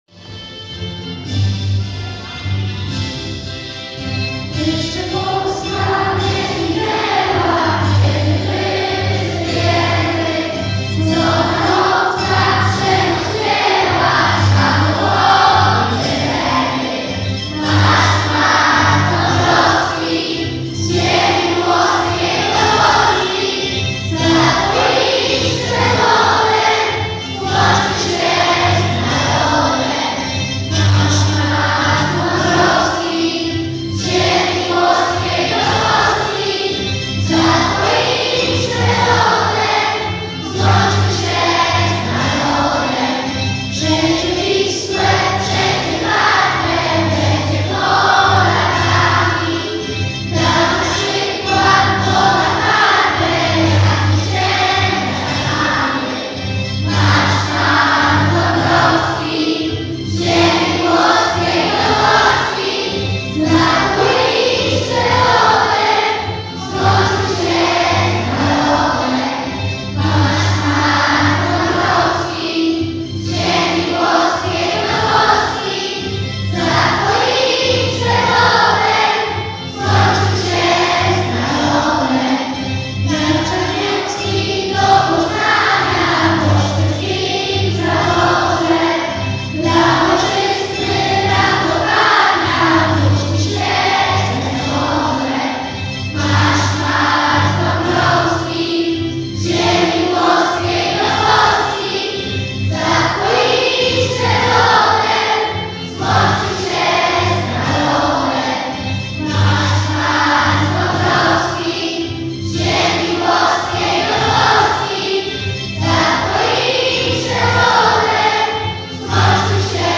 Uroczystość rozpoczęto o godz. 11.11  zaśpiewaniem czterech zwrotek hymnu państwowego.